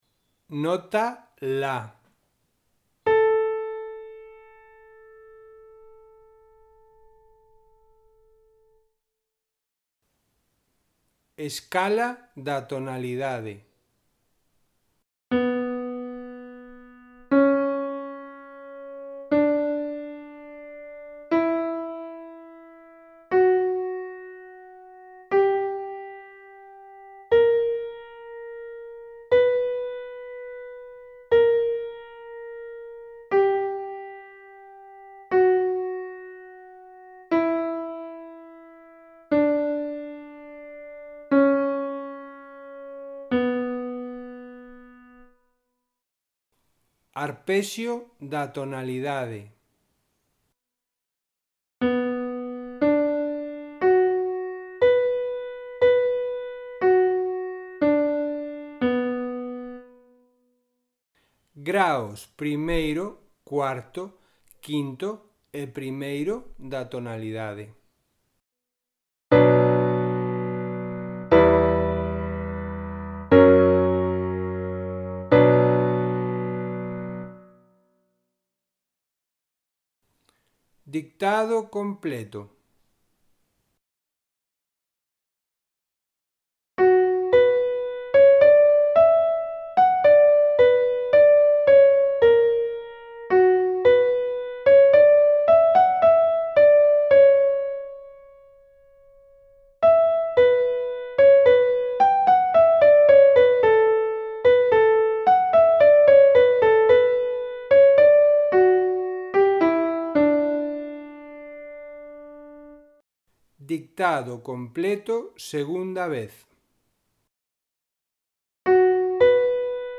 Ditados a 1 voz
Nos audios tendes o ditado completo, dende a escala e os acordes, ata a secuencia de repeticións; i ntentade facelo escoitando o audio unha soa vez.